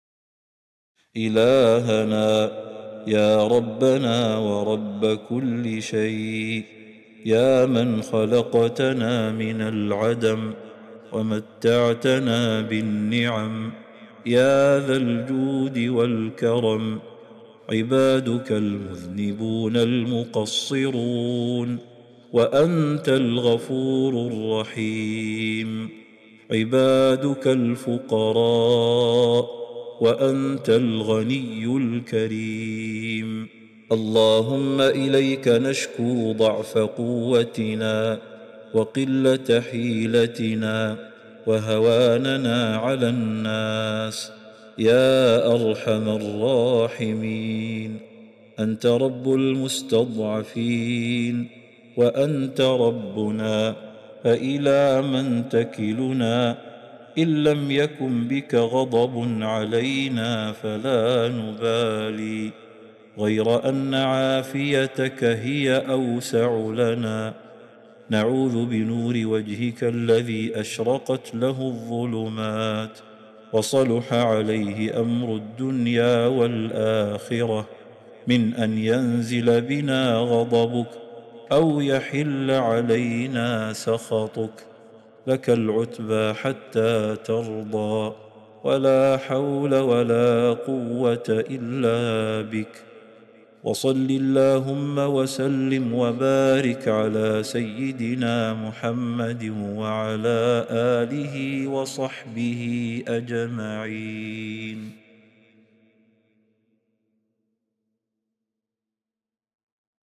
دعاء خاشع ومناجاة مؤثرة يعترف فيها العبد بذنوبه وتقصيره وفقره أمام غنى وكرم الله تعالى. يتضمن التضرع إلى الله وطلب العافية والاستعاذة من سخطه، مع التوسل بنور وجهه الكريم والصلاة على النبي محمد.